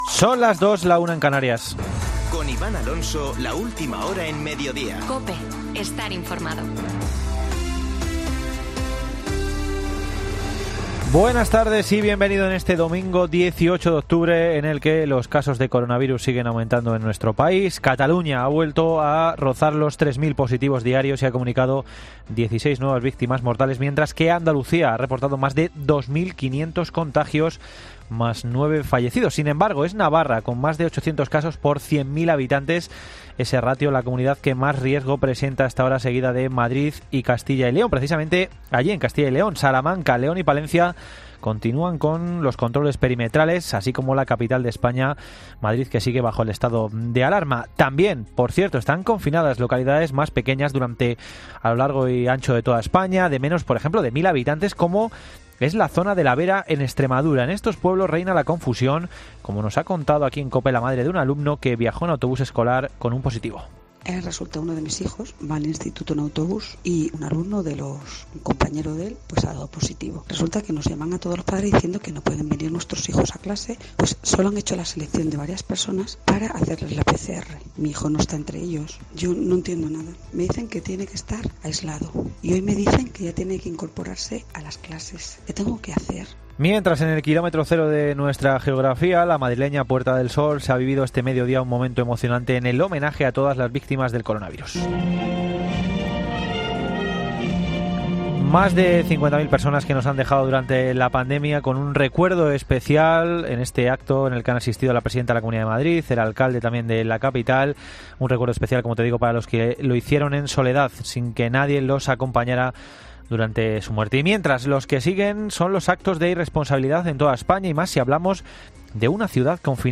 Boletín de noticias de COPE del 18 de Octubre de 2020 a las 14.00 horas